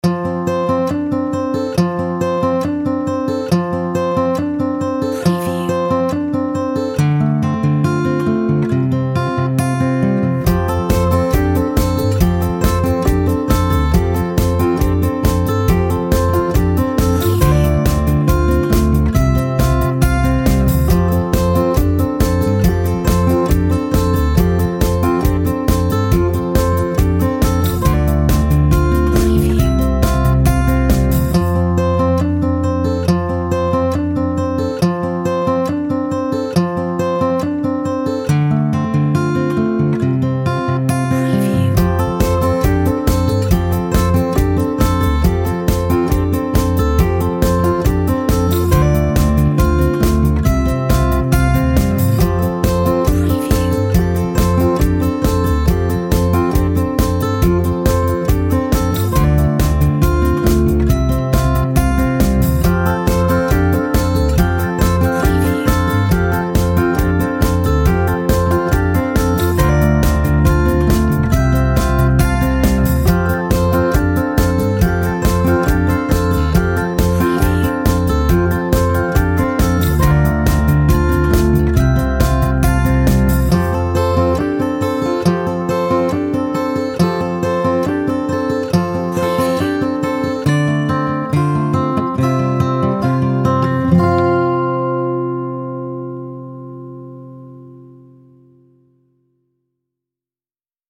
Sweet and simple